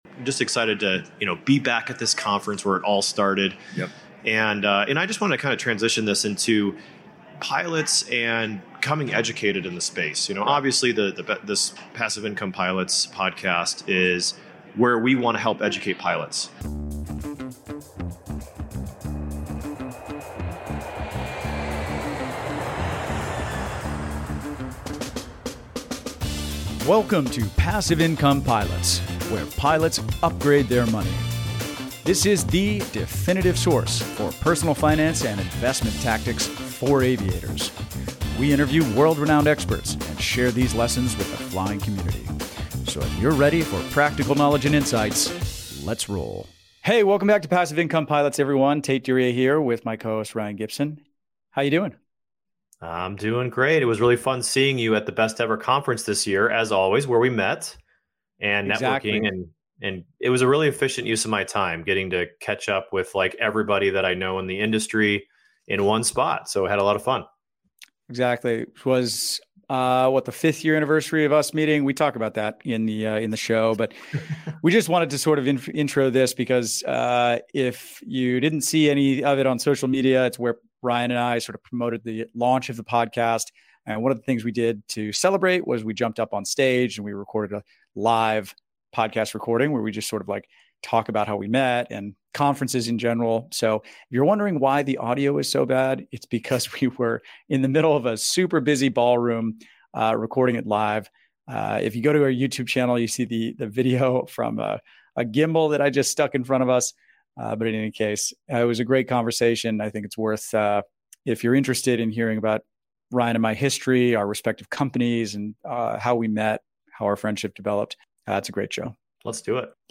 #5 - Live at the BEC 2023 (Where It All Started) | The #1 Financial Resource for Pilots | Where Pilots Upgrade Their Money